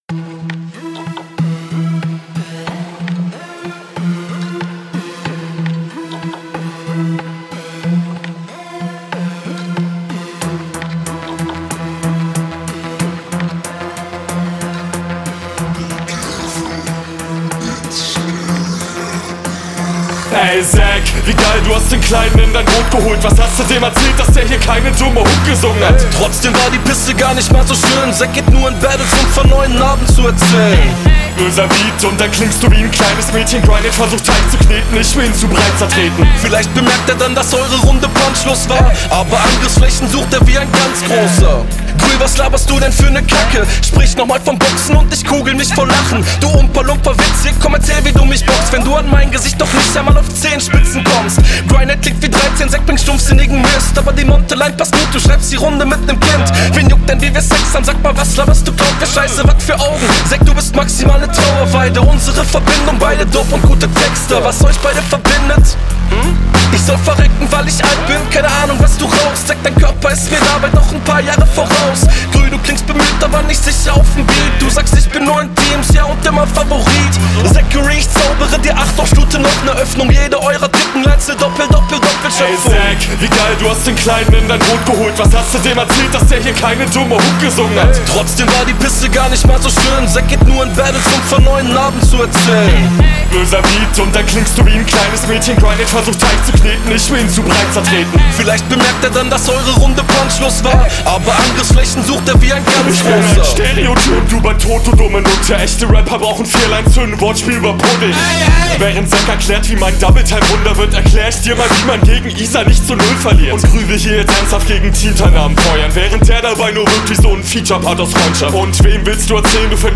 Chorus auch sehr nice mit Konter.